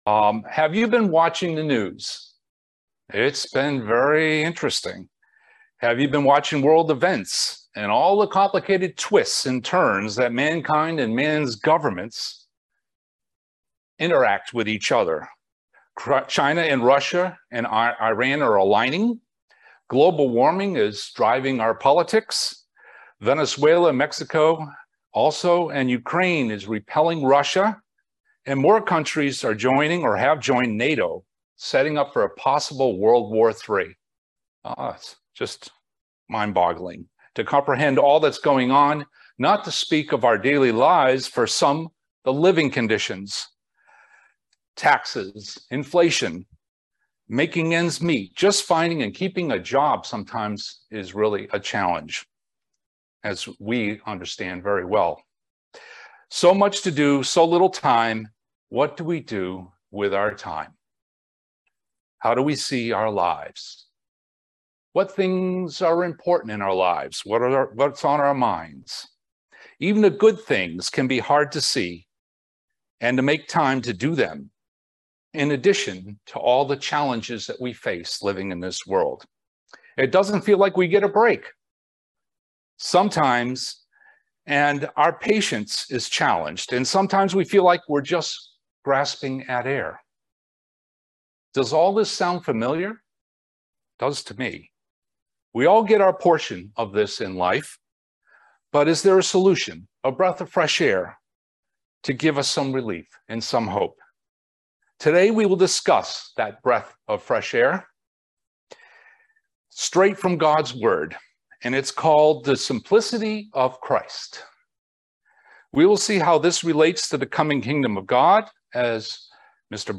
This sermon discusses the child-like characteristics that we need to develop to enter the Kingdom of God. He does this through comparing a child and the parent's relationship with that of ours, and our Father and Jesus Christ.